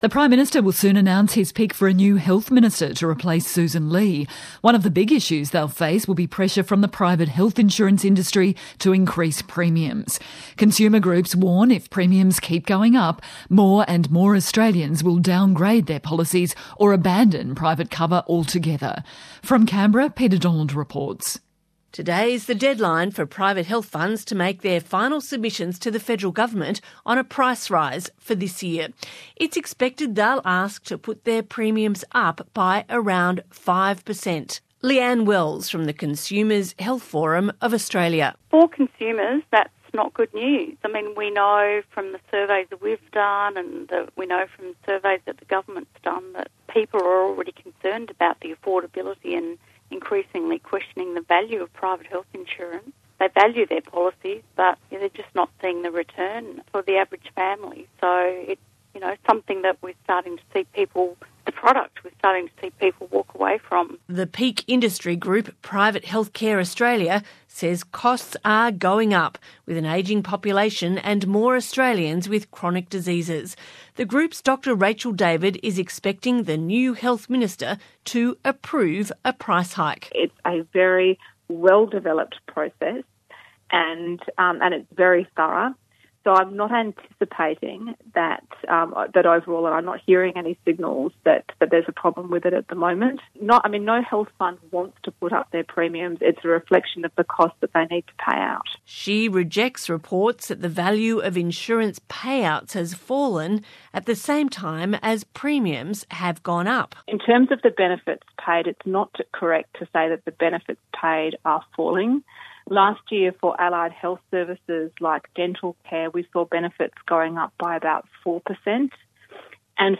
Station: ABC RADIO CANBERRA